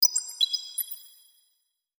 Riochet Notification 2.wav